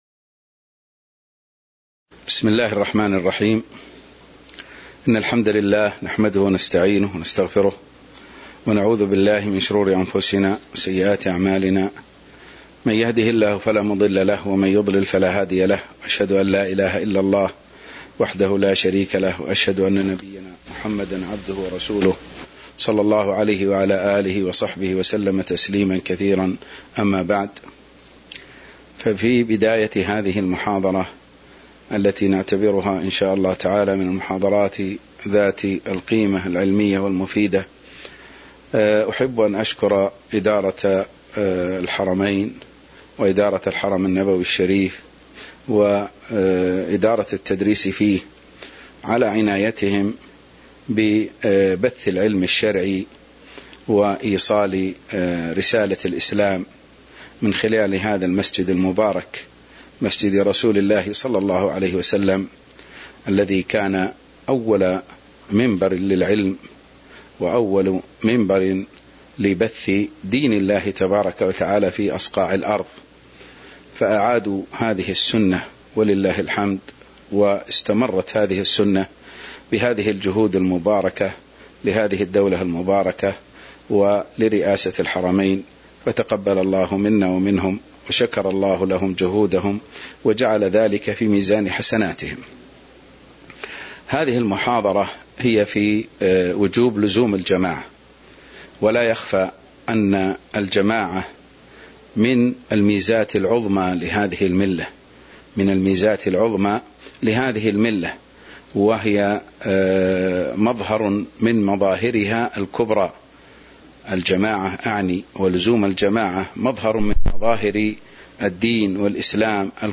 تاريخ النشر ١٤ صفر ١٤٤٢ هـ المكان: المسجد النبوي الشيخ